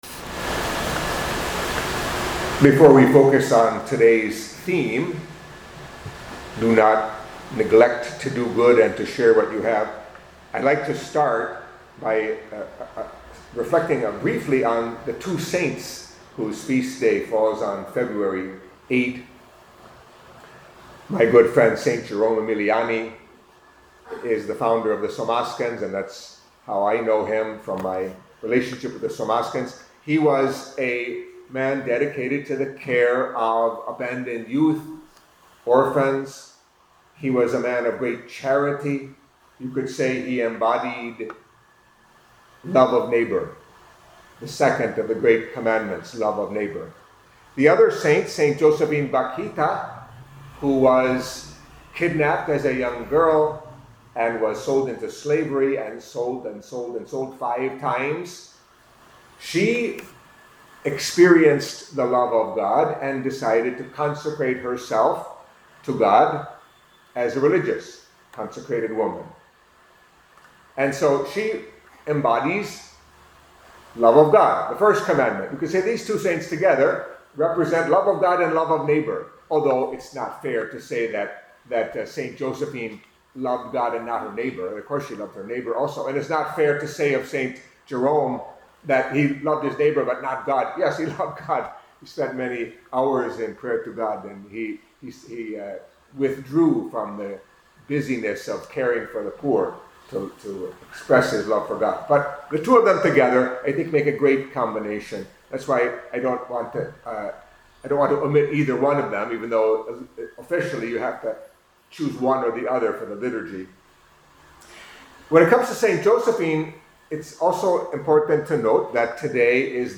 Catholic Mass homily for Saturday of the Fourth Week in Ordinary Time